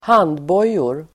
Uttal: [²h'an:dbå:jor]
handbojor.mp3